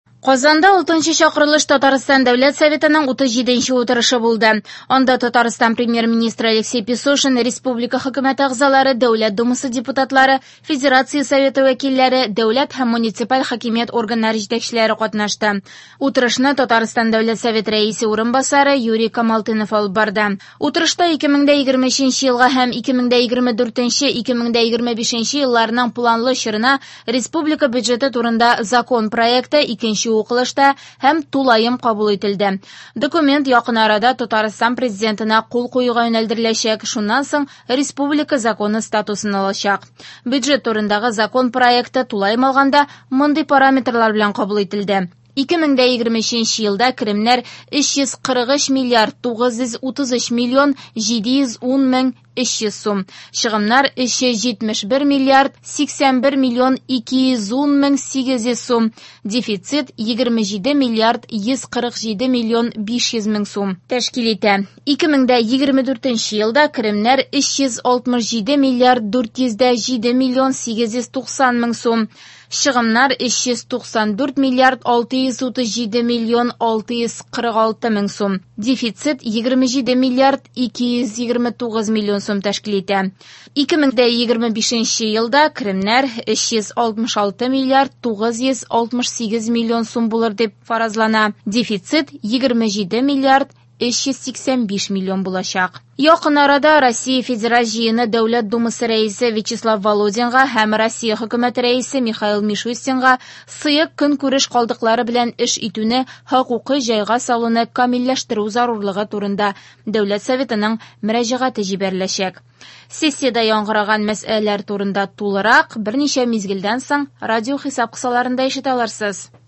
Радиоотчет (18.11.22) | Вести Татарстан
В эфире специальный информационный выпуск , посвященный 37 заседанию Государственного Совета Республики Татарстан 6-го созыва.